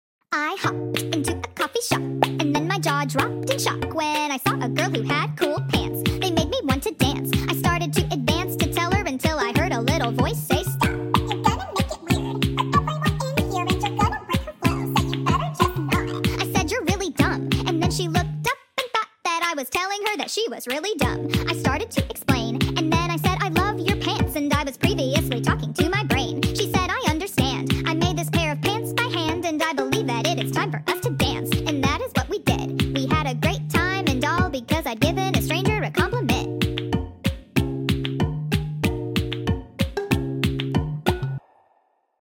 OMG sound effects free download